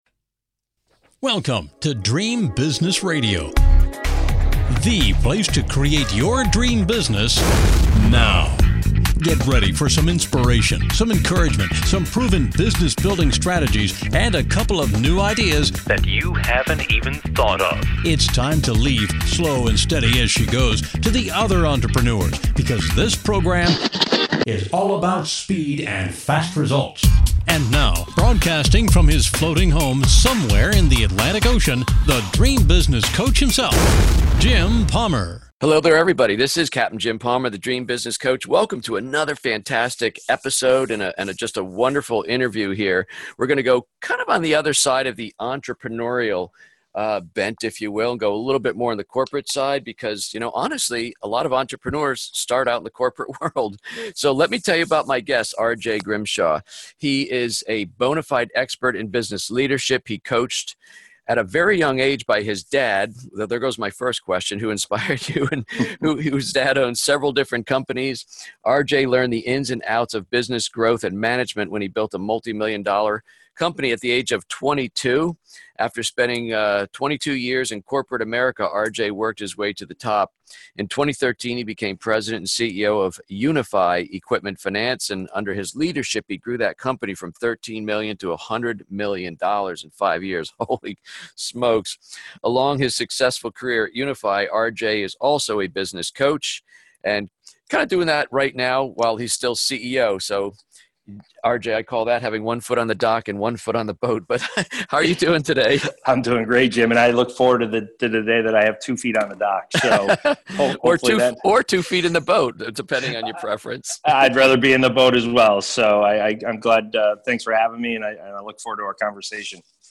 On this week’s show I interview CEO